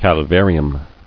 [cal·var·i·um]